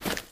High Quality Footsteps
STEPS Dirt, Run 09.wav